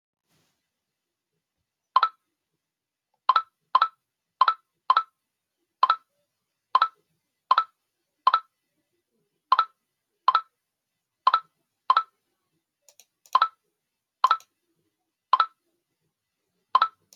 Snapchat Notification Sound Button - Free Download & Play
1. Play instantly: Click the sound button above to play the Snapchat Notification sound immediately in your browser.